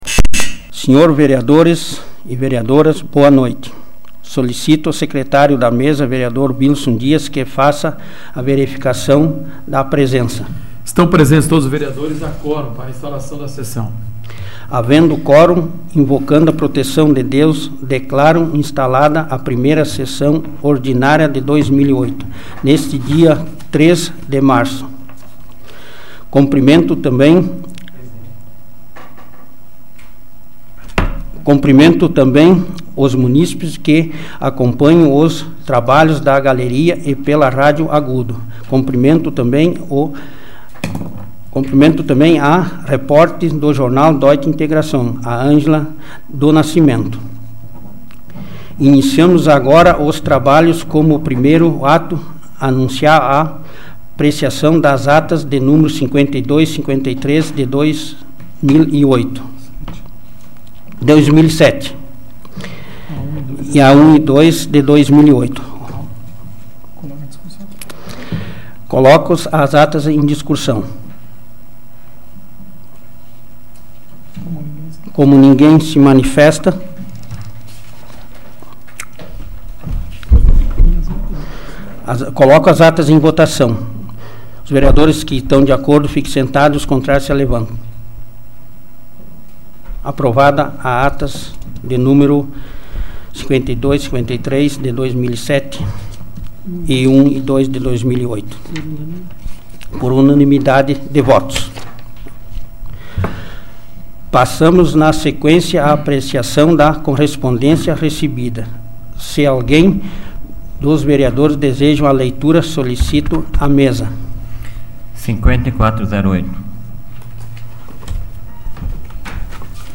Áudio da 112ª Sessão Plenária Ordinária da 12ª Legislatura, de 03 de março de 2008